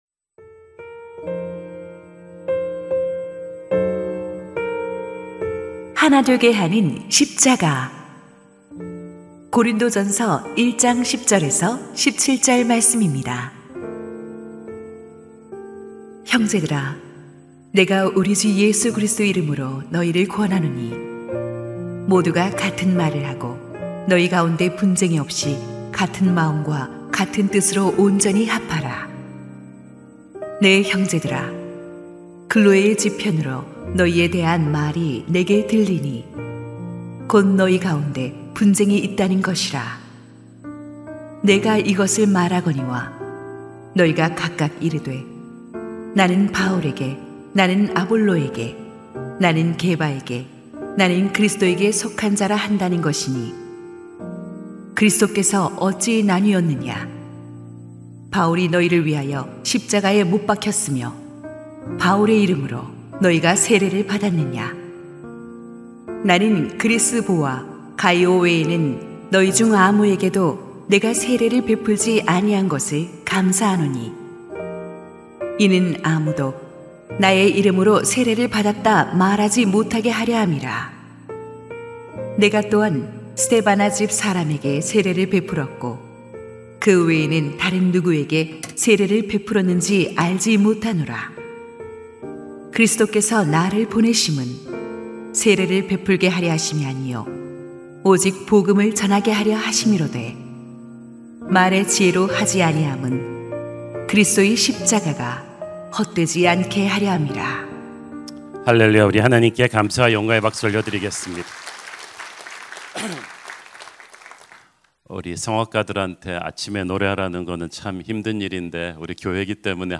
2026-03-31 고난주간 특별새벽기도회
> 설교